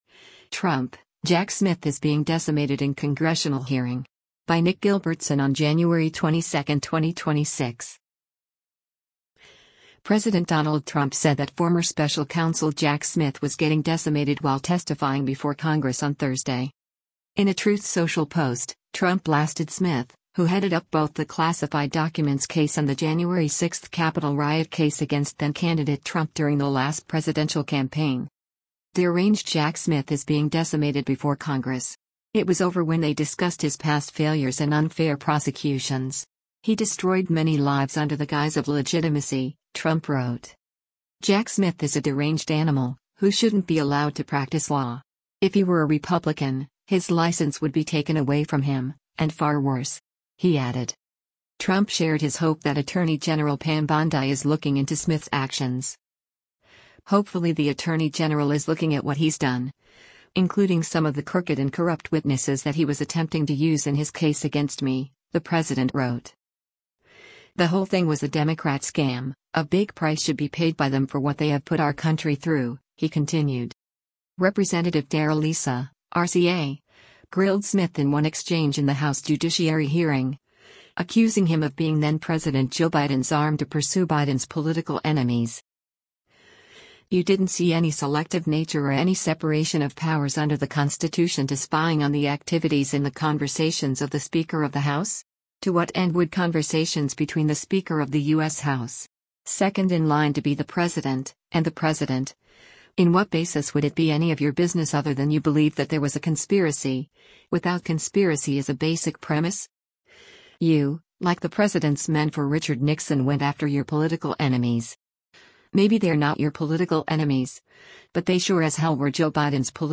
Former US special counsel Jack Smith, testifies before the House Judiciary Committee about
Rep. Darrell Issa (R-CA) grilled Smith in one exchange in the House Judiciary hearing, accusing him of being then-President Joe Biden’s “arm” to pursue Biden’s political enemies:
Issa’s questioning came to a close after a fiery exchange with Ranking Member Jamie Raskin (D-MD).